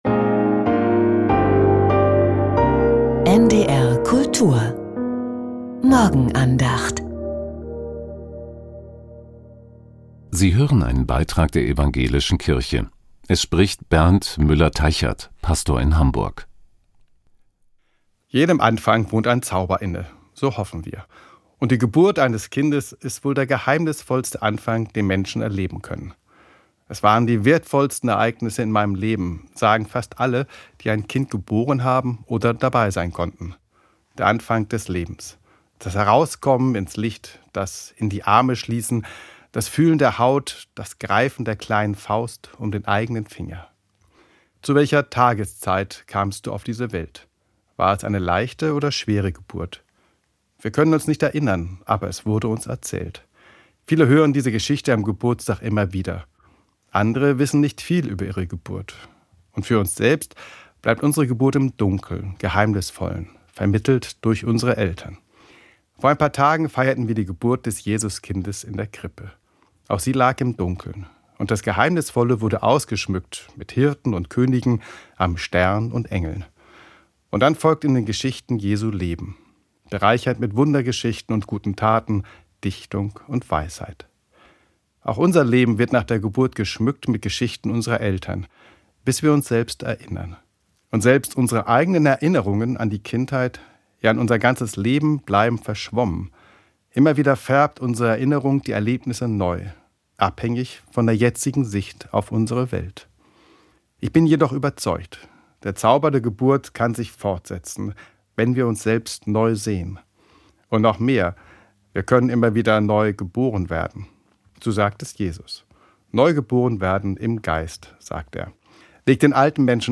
Vom Zauber der Geburt ~ Die Morgenandacht bei NDR Kultur Podcast